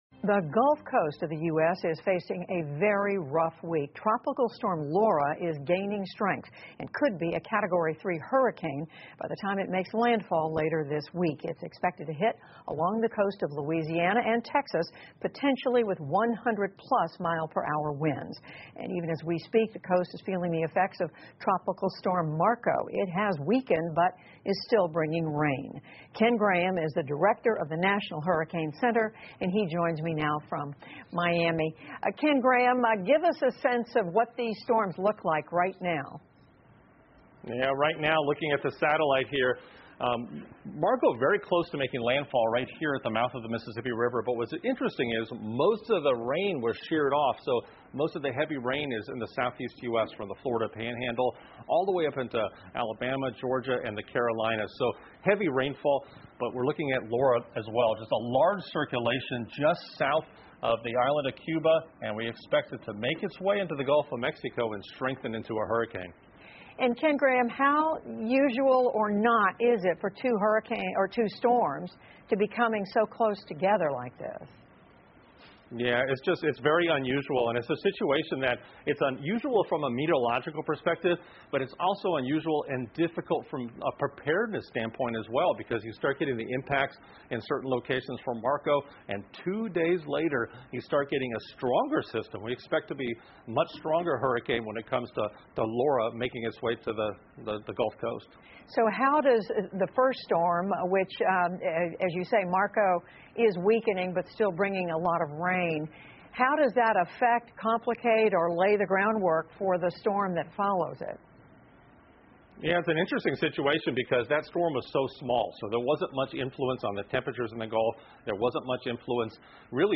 PBS高端访谈:墨西哥湾海岸遭遇两场飓风威胁 听力文件下载—在线英语听力室